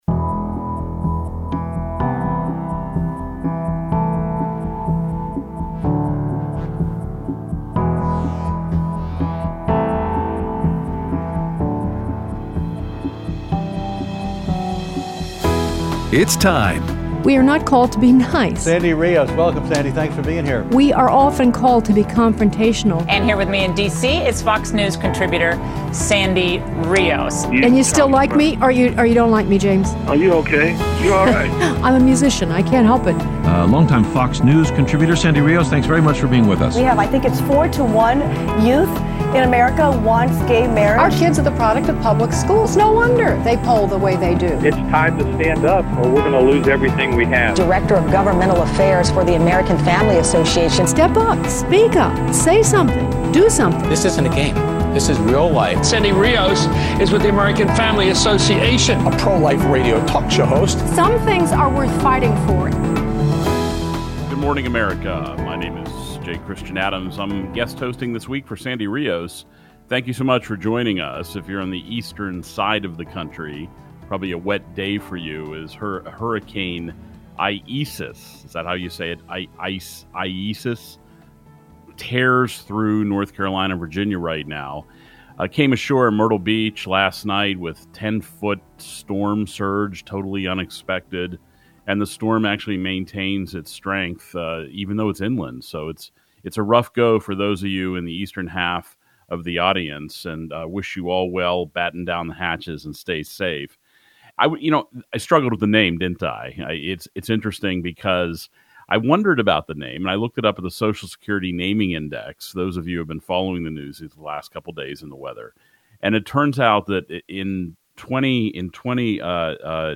Guest Host: J. Christian Adams Discusses Mail In Ballots and America's Schools
Aired Tuesday 8/4/20 on AFR 7:05AM - 8:00AM CST